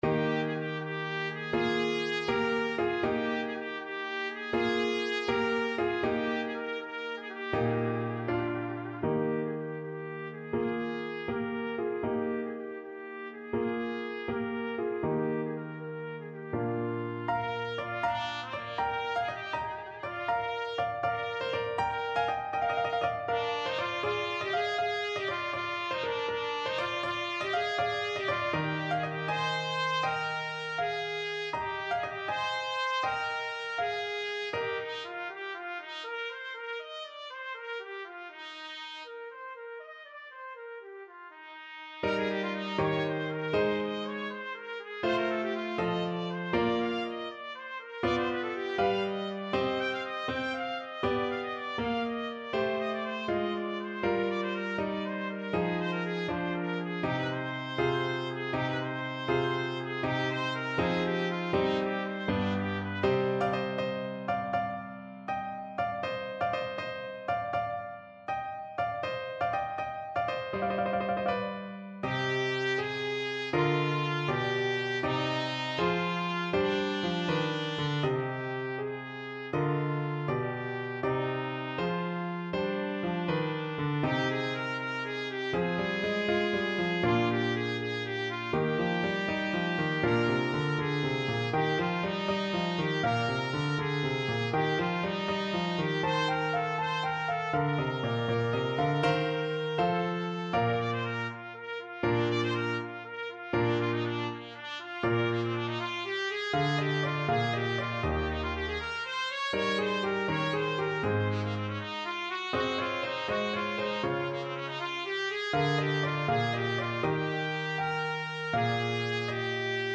Danza Pastorale Trumpet version
Eb major (Sounding Pitch) F major (Trumpet in Bb) (View more Eb major Music for Trumpet )
Allegro (View more music marked Allegro)
12/8 (View more 12/8 Music)
Trumpet  (View more Advanced Trumpet Music)
Classical (View more Classical Trumpet Music)
vivaldi_spring_3_danza_TPT.mp3